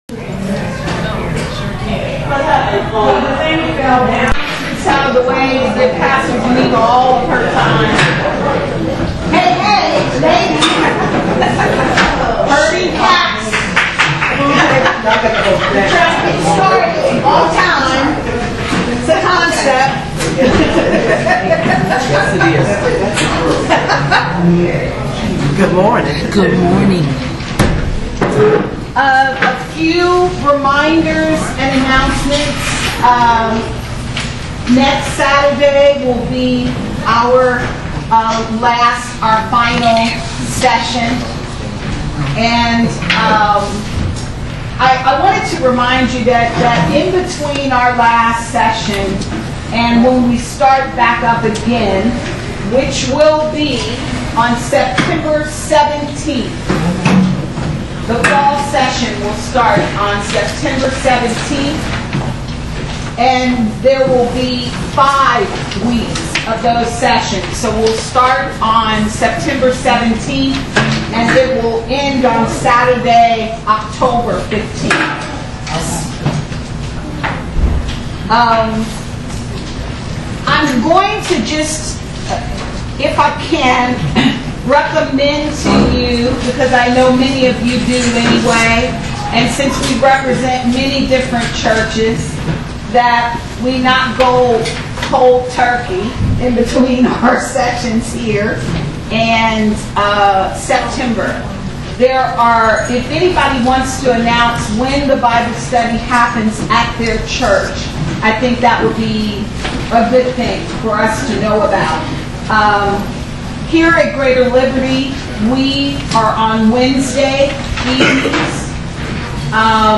Bible Study Recordings